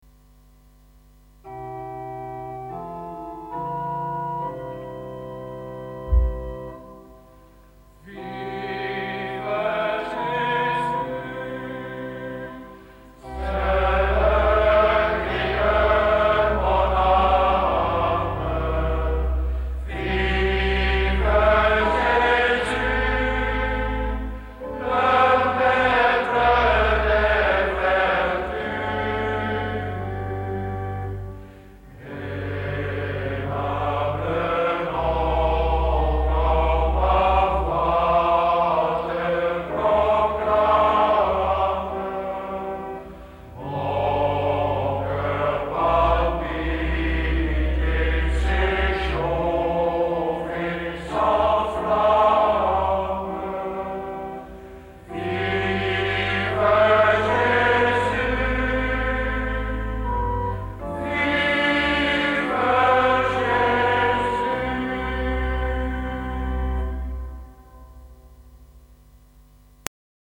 Op Rolduc zijn bij diverse gelegenheden veel liederen gezongen, meestal in het Frans.